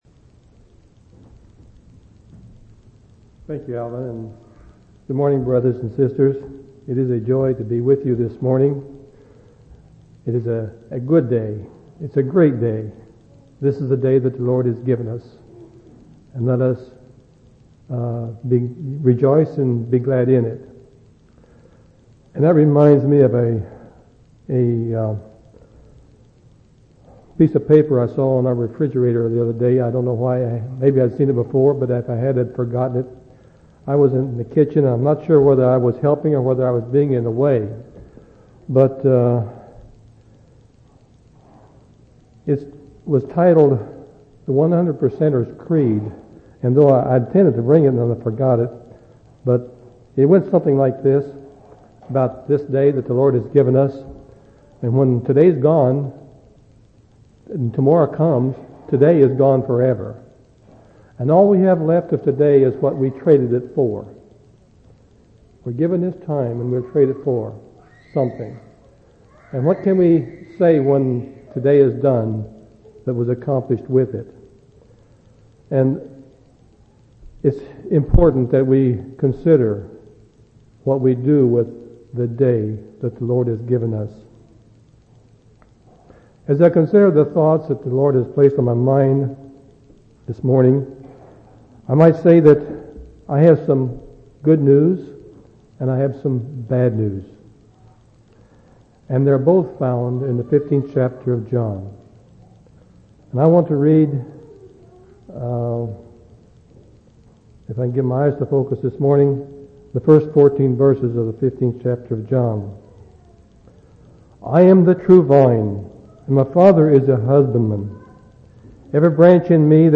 8/16/1996 Location: Missouri Reunion Event